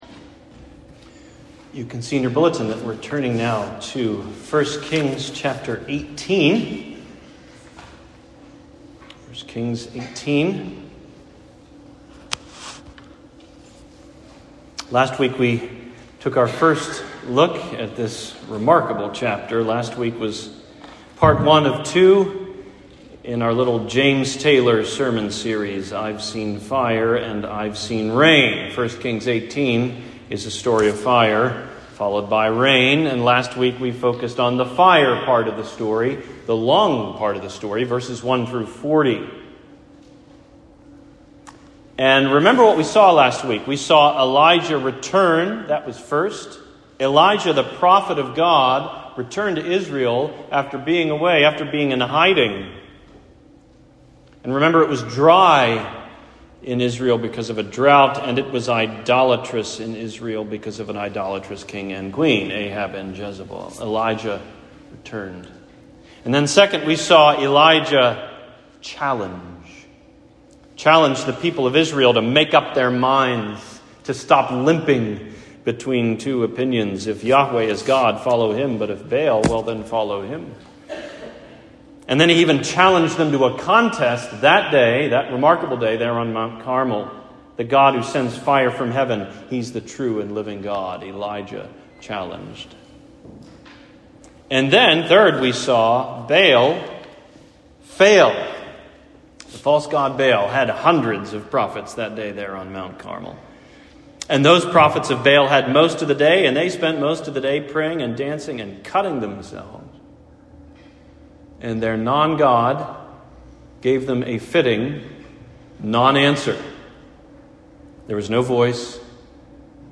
Fire and Rain (Part 2): Sermon on 1 Kings 18:41-46